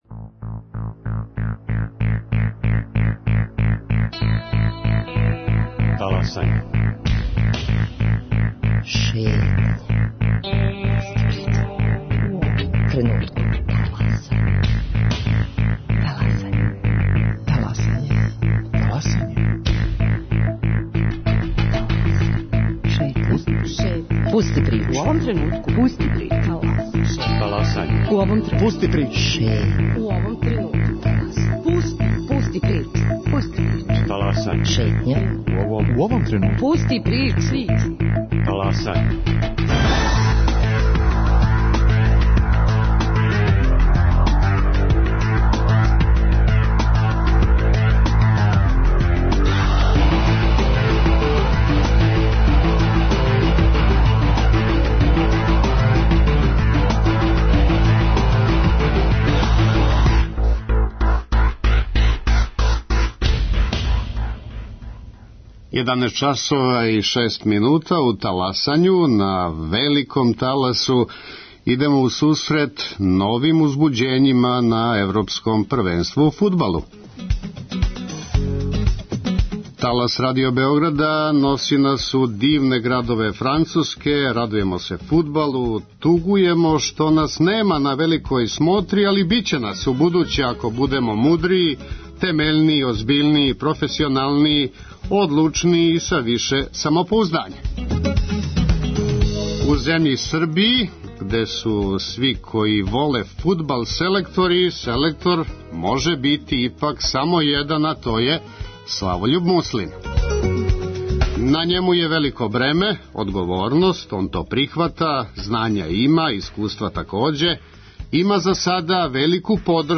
О изненађењима на Европском шампионату у Француској, али и о плановима наше репрезентације, разговарамо са селектором Славољубом Муслином.